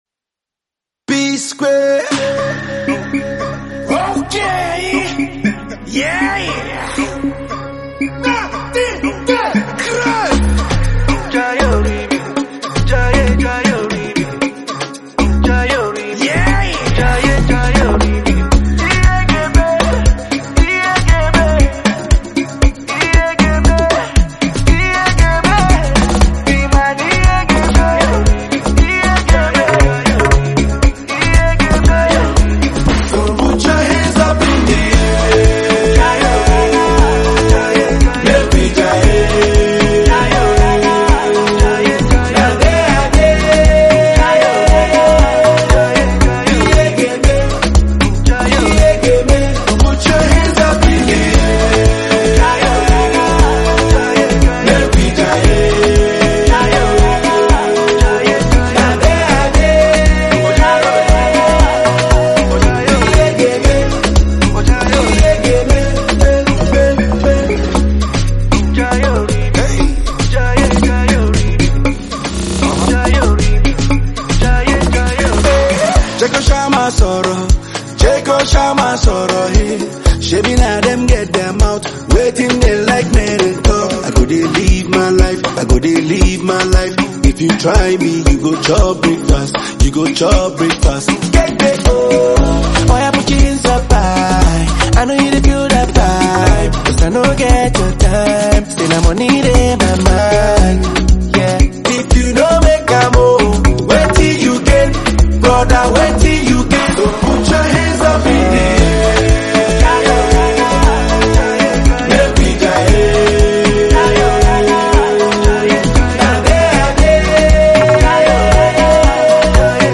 Afrobeats
club bangers